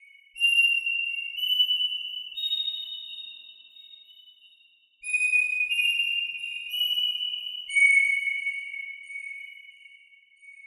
Index of /DESN275/loops/Loop Set - Spring - New Age Ambient Loops/Loops
Generosity_90_B_SynthMelody.wav